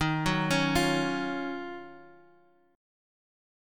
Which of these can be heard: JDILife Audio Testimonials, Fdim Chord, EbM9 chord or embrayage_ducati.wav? EbM9 chord